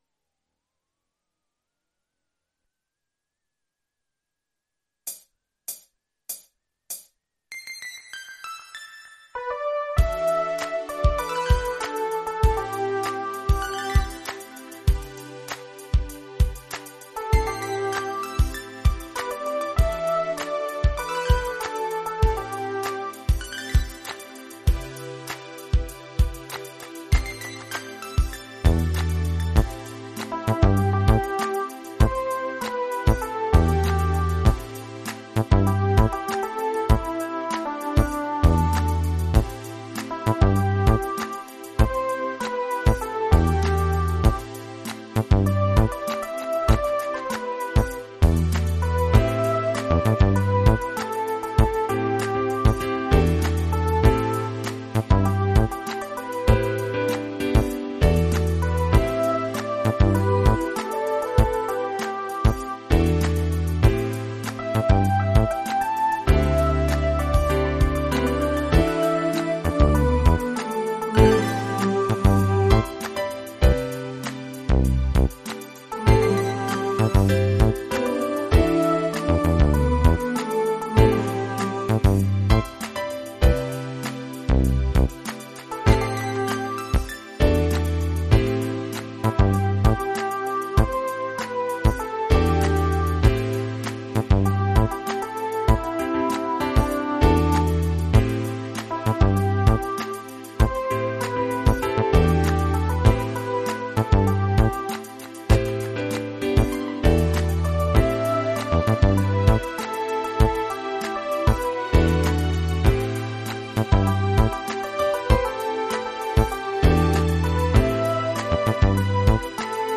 version instrumentale multipistes
au format MIDI Karaoke pro.